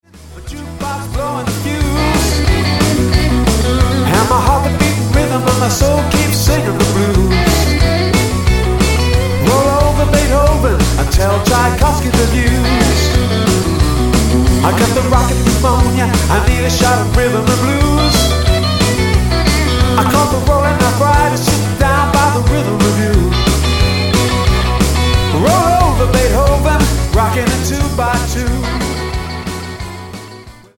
Tonart:B Multifile (kein Sofortdownload.
Die besten Playbacks Instrumentals und Karaoke Versionen .